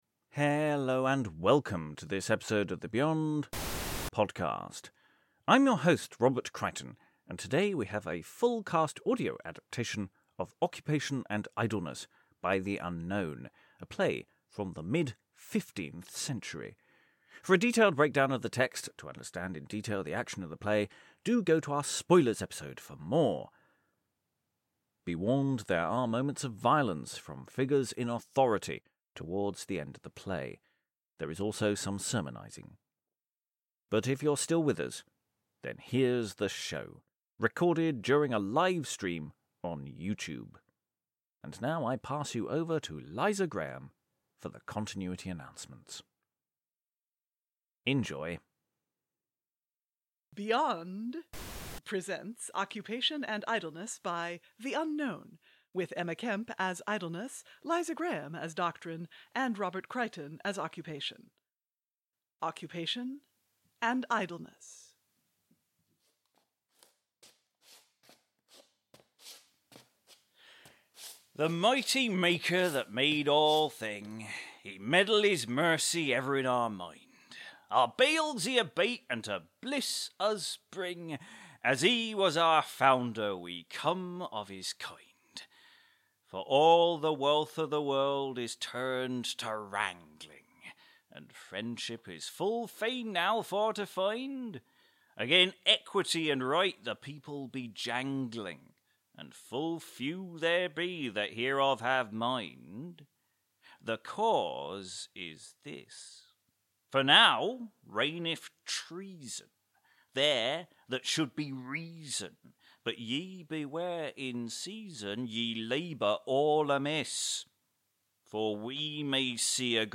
Full Cast Audio adaptation of this very early drama
It's our full cast audio adaptation of Occupation and Idleness by the Unknown - recorded live on YouTube, and mixed for your delectation and pleasure.